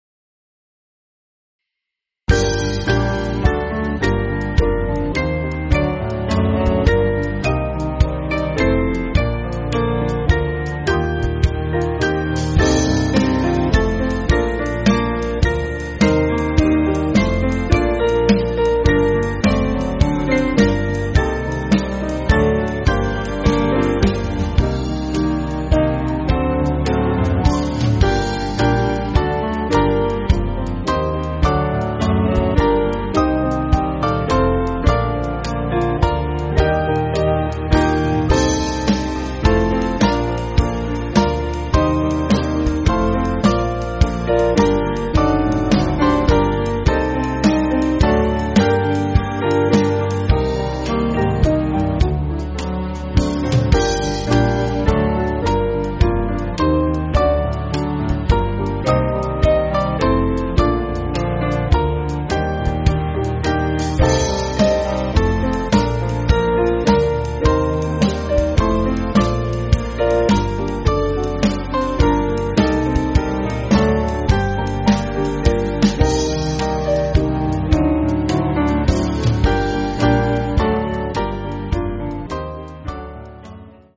Small Band
(CM)   6/Gm